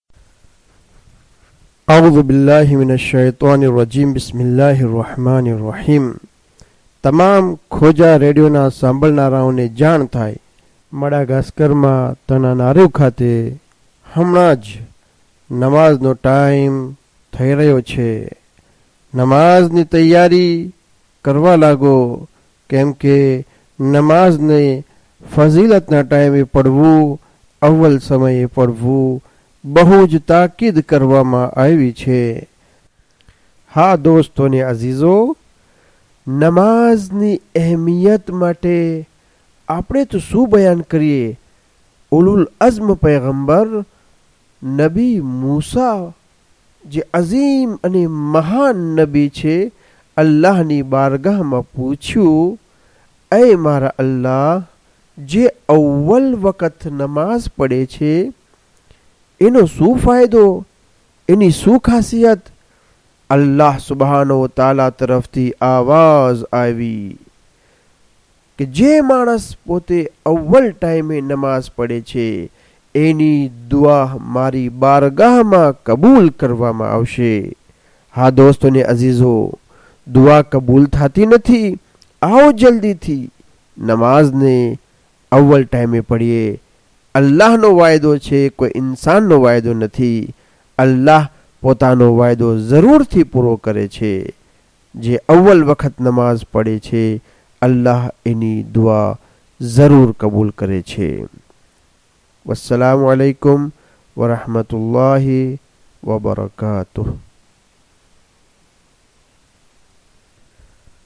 3 azan tana.mp3